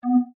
bdspeech_recognition_error.mp3